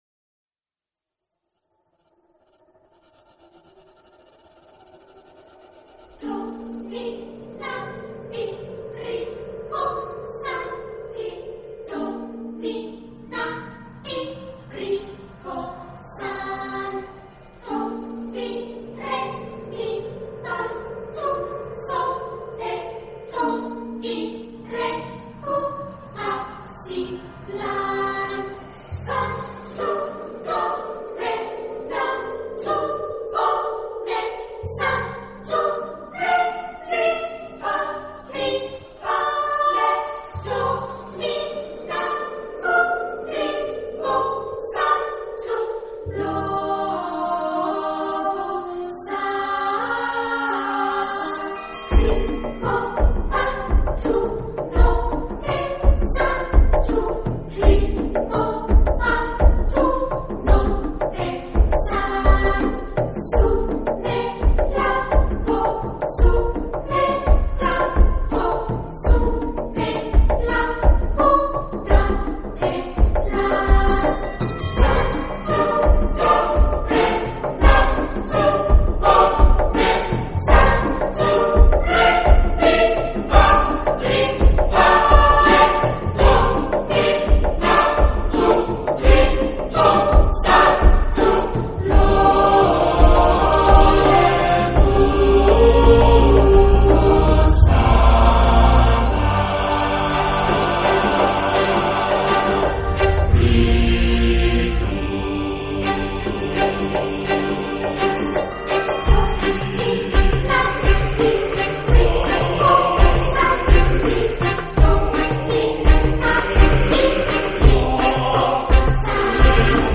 Soundtrack, Horror